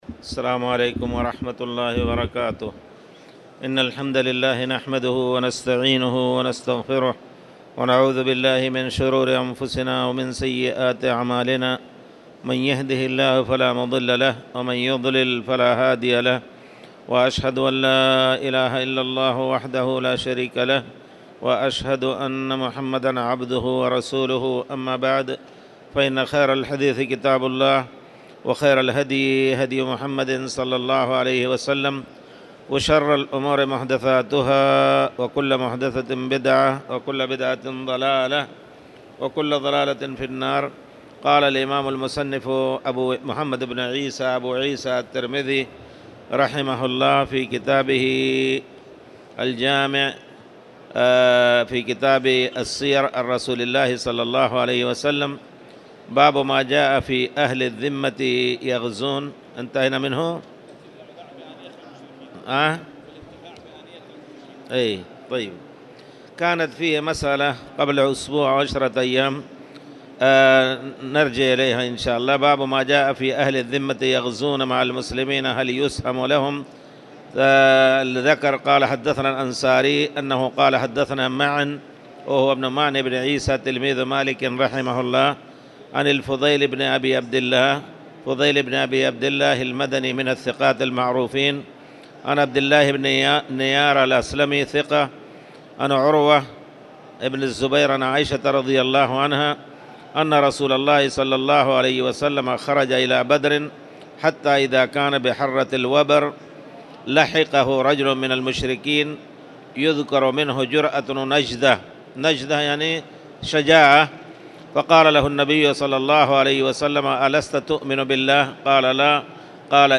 تاريخ النشر ١٥ رجب ١٤٣٨ هـ المكان: المسجد الحرام الشيخ